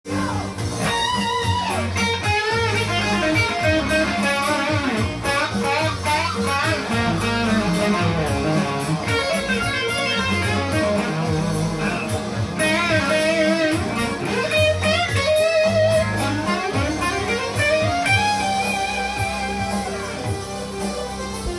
ギターソロtab譜
音源に合わせて譜面通り弾いてみました
冒頭からチョップ奏法
ブルージーなマイナーペンタトニックスケール
そしてピッキングハーモニクス
更にハンマリングとプリングの連続　レガート奏法
その後にスィープ奏法
そして最後にまたレガート奏法